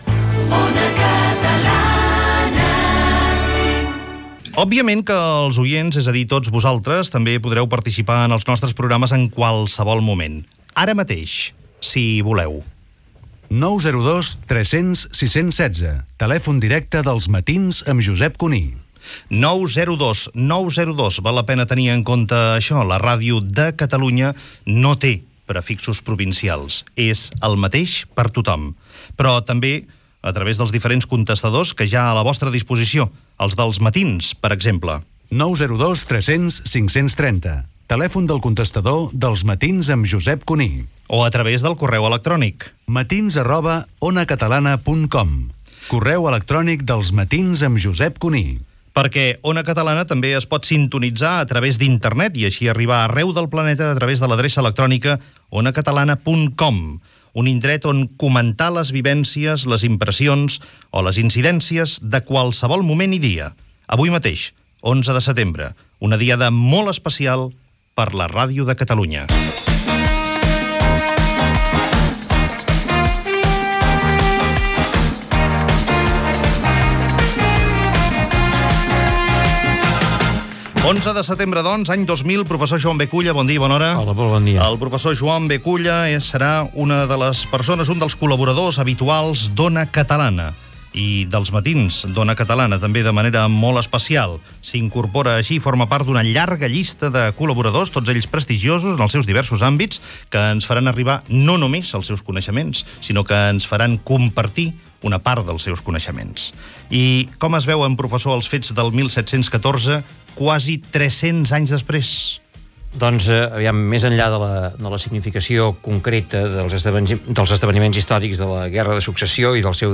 Inducatiu de la ràdio, crida a la participació dels oients i formes com ho poden fer. Intervenció de l'historiador Joan B. Culla.
Info-entreteniment